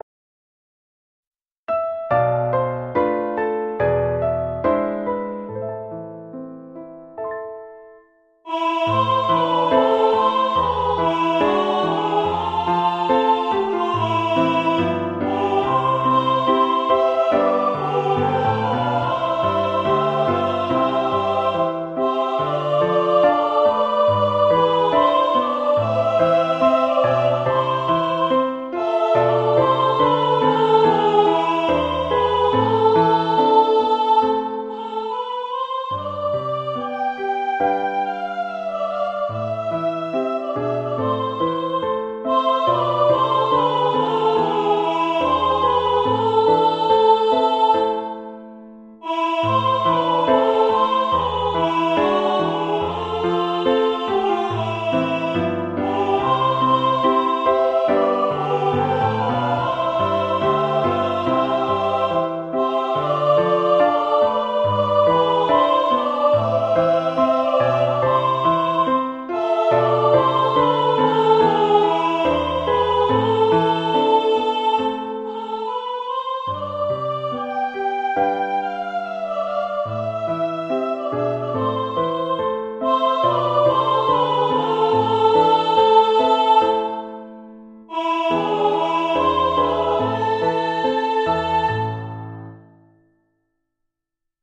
Ноты для фортепиано, вокальная партитура